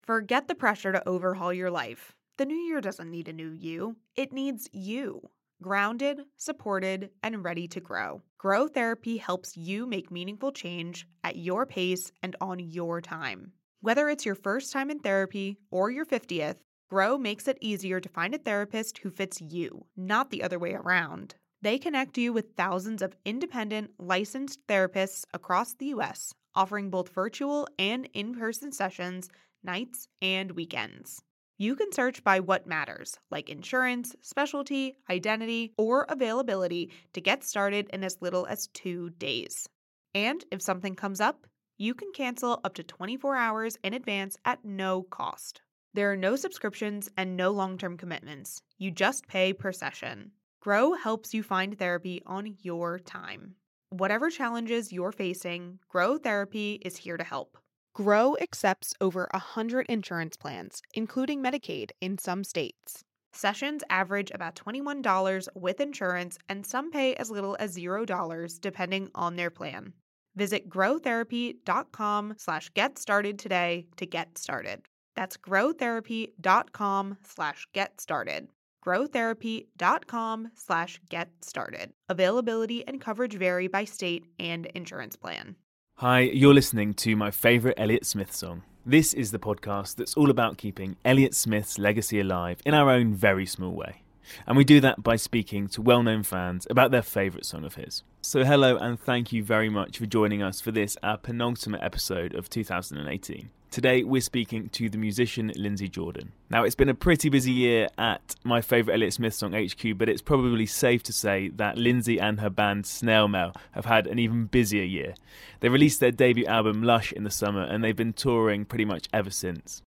Our guest for episode 24 of My Favourite Elliott Smith Song is Lindsey Jordan.
So we were really lucky to catch her during some rare time off at her home near Baltimore, Maryland (USA) to talk through her favourite song pick, as well as how she finds rare Elliott tracks and why she connects so much with musicians who made their names during the 90s.